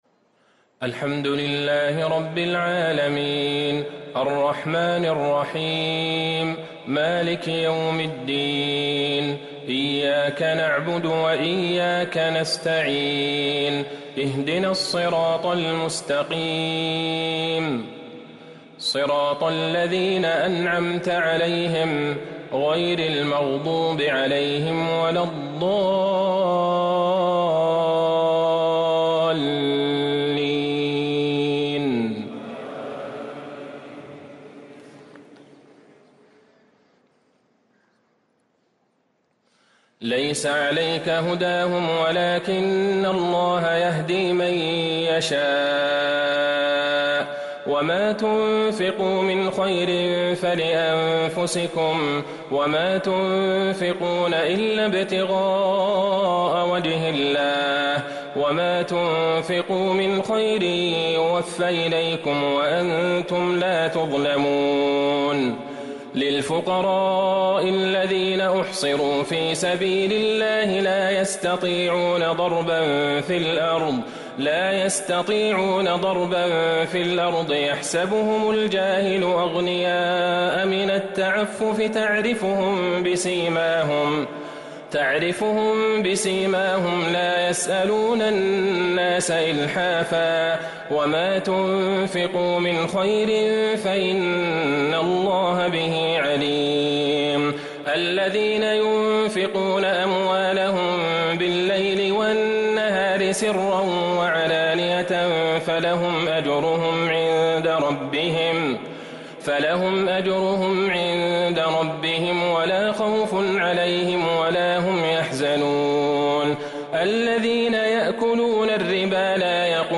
تراويح ليلة 4 رمضان 1444هـ من سورتي البقرة {272 -286} و آل عمران {1-41} Taraweeh 4st night Ramadan 1444H Surah Al-Baqara Surah Aal-i-Imraan > تراويح الحرم النبوي عام 1444 🕌 > التراويح - تلاوات الحرمين